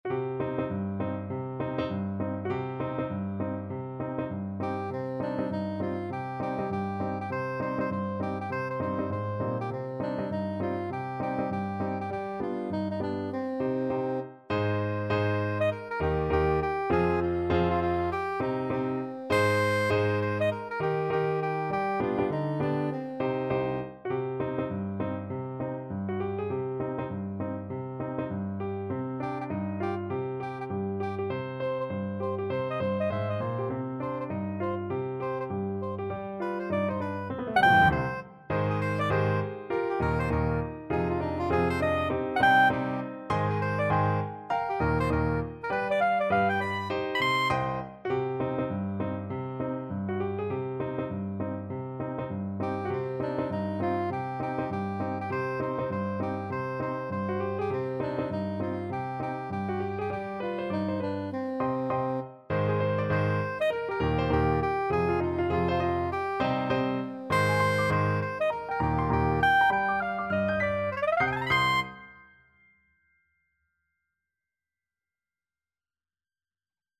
2/2 (View more 2/2 Music)
Firmly, with a heart of oak! Swung = c.100
Traditional (View more Traditional Soprano Saxophone Music)